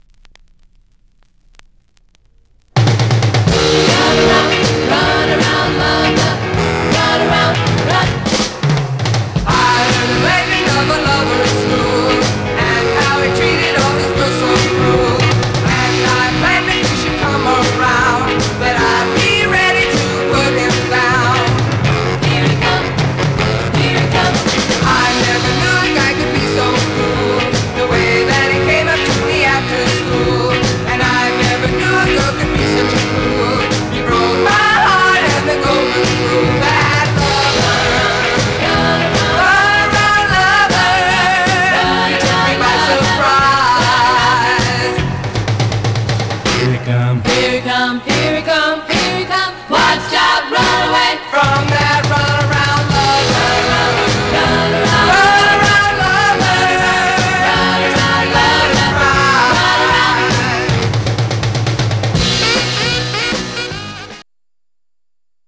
Stereo/mono Mono
Surf